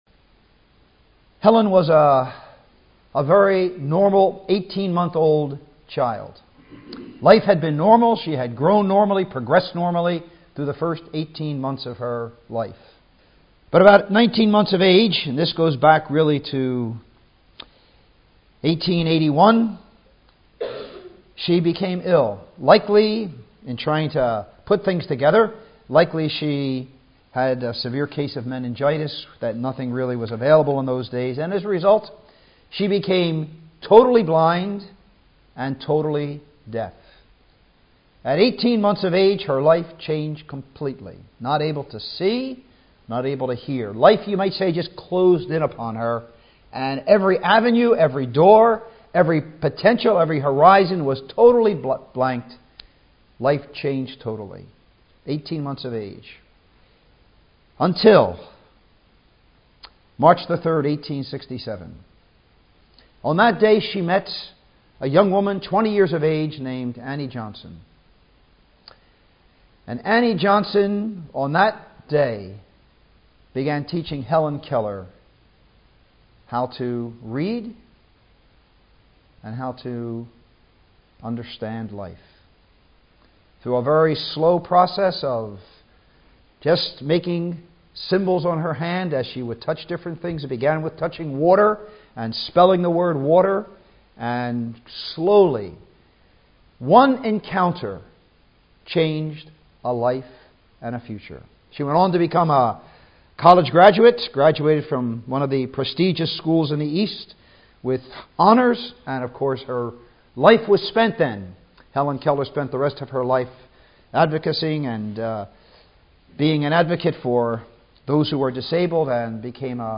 2015 Easter Conference